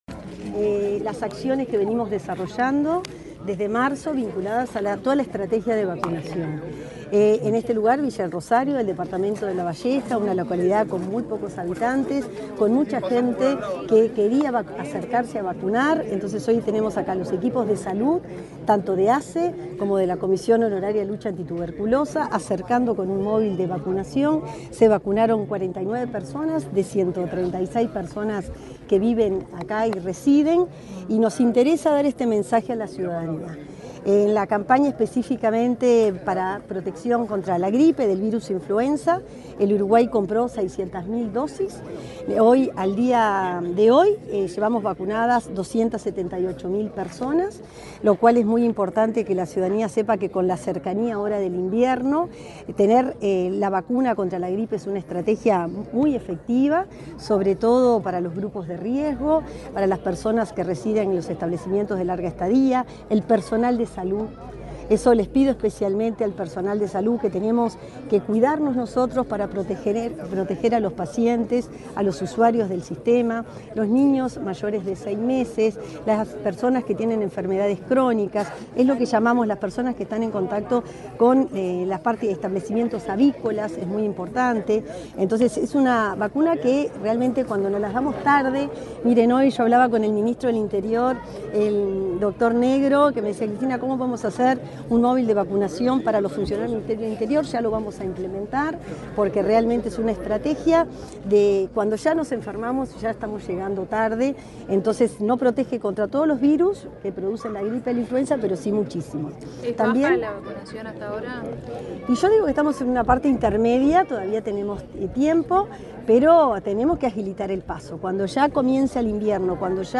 Declaraciones de la ministra de Salud Pública, Cristina Lustemberg
La ministra de Salud Pública, Cristina Lustemberg, dialogó con la prensa en Lavalleja, acerca de la presentación del plan de vacunación en ese